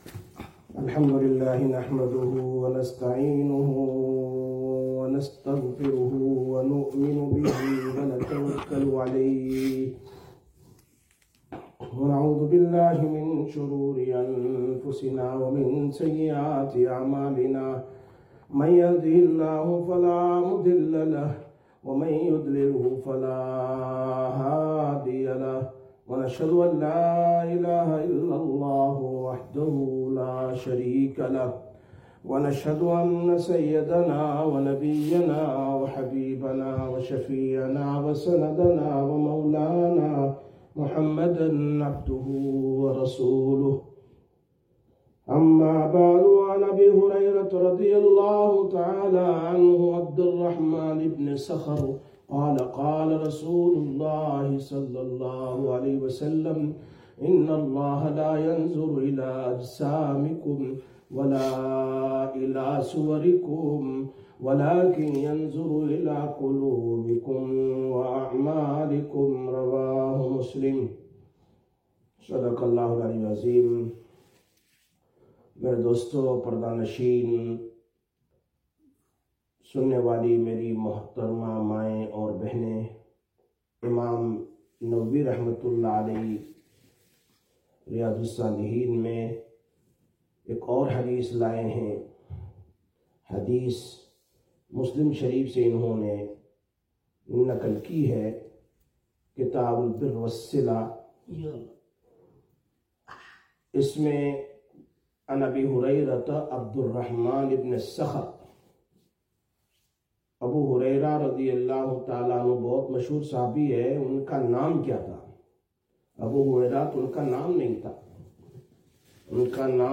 18/12/2024 Sisters Bayan, Masjid Quba